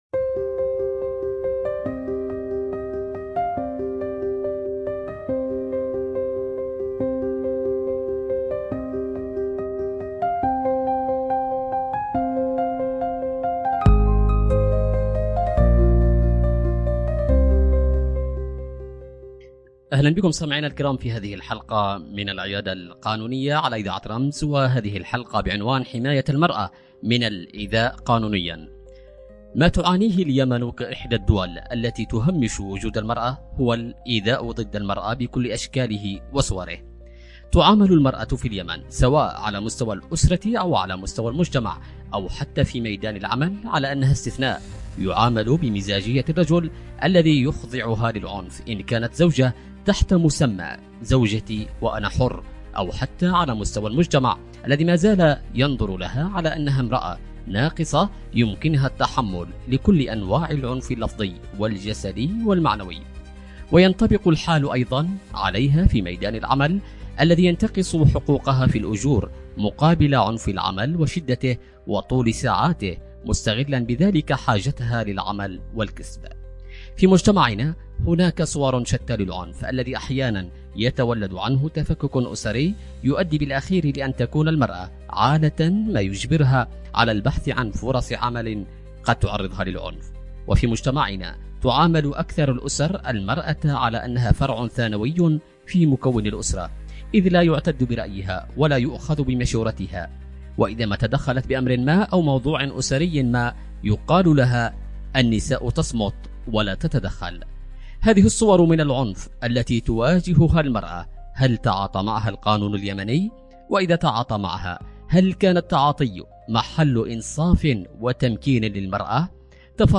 في نقاشٍ غنيّ يتناول قضايا الإيذاء ضد المرأة من منظور قانوني واجتماعي
عبر أثير إذاعة رمز